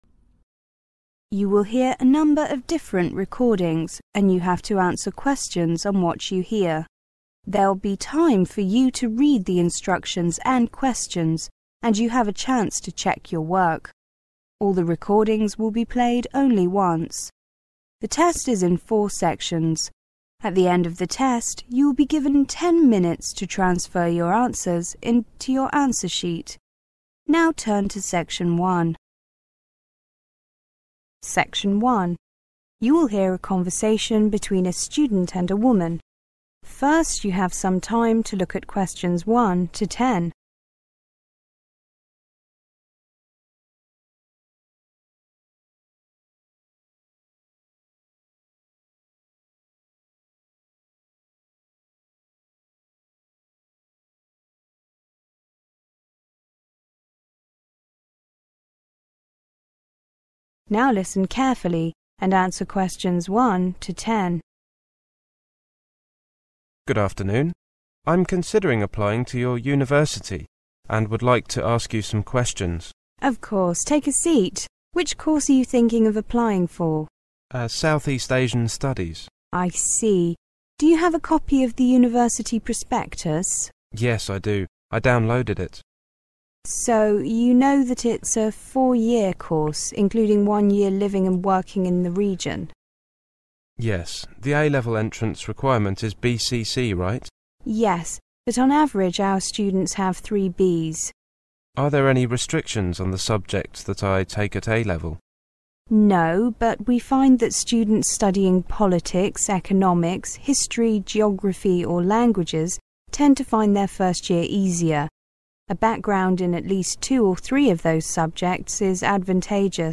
Section 1 is a conversation between a student and a woman about admission in the university.
Section 2 is a lecture by an Indian sociologist on Indian youth.